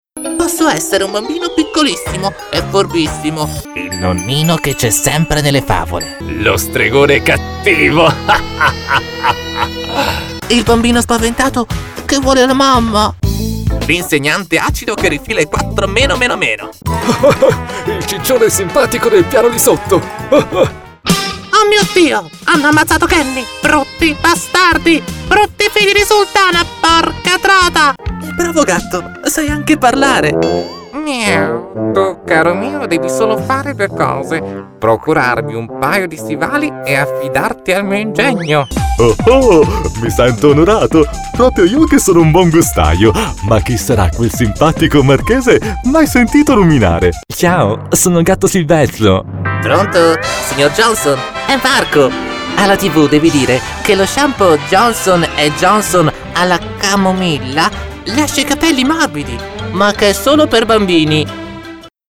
Naturelle, Distinctive, Amicale, Chaude